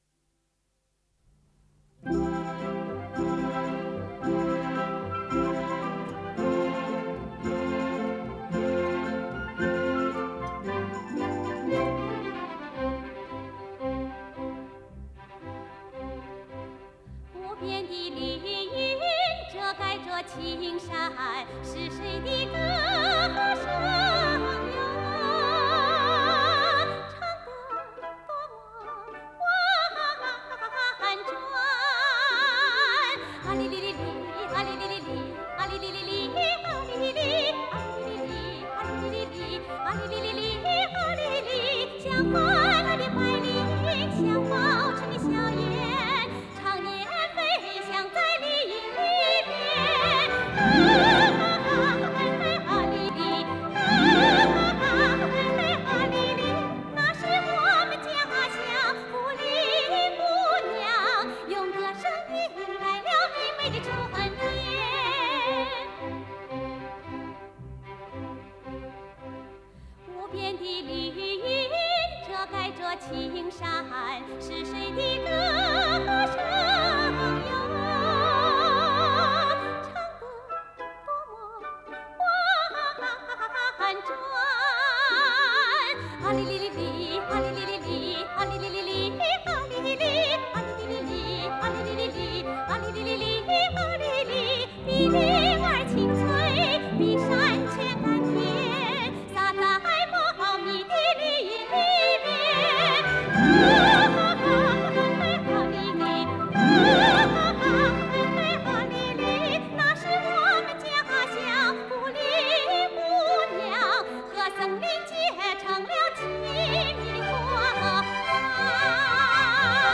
这是一首花腔歌曲。